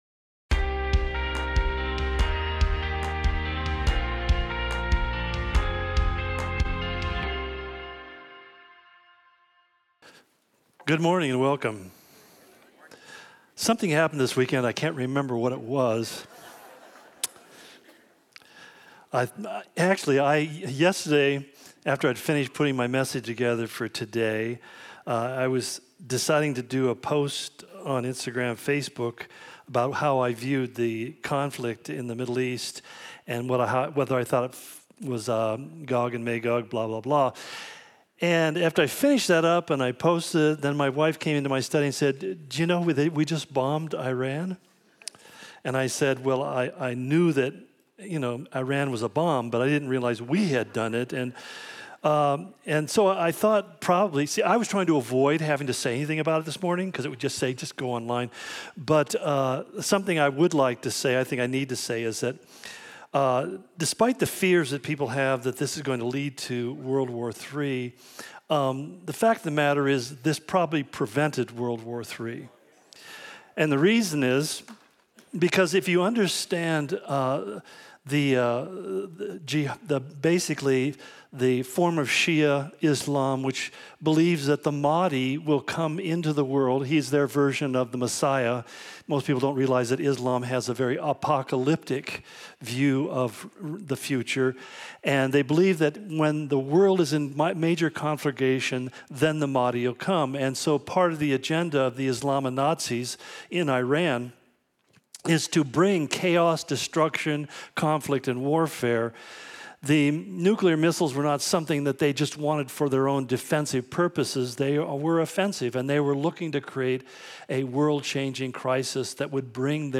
The Unveiling - Part 83 Completely New! Calvary Spokane Sermon Of The Week podcast